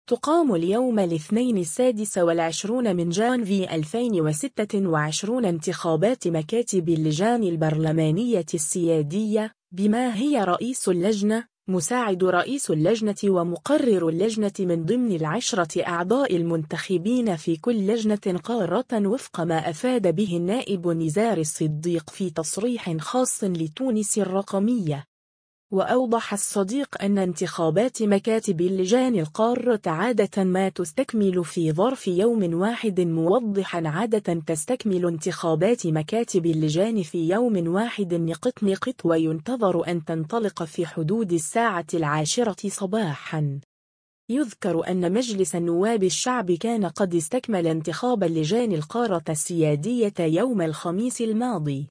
تُقام اليوم الاثنين 26 جانفي 2026 انتخابات مكاتب اللجان البرلمانية السيادية،بما هي رئيس اللجنة،مساعد رئيس اللجنة ومقرر اللجنة من ضمن العشرة أعضاء المنتخبين في كل لجنة قارة وفق ما أفاد به النائب نزار الصديق في تصريح خاص لـ”تونس الرقمية”.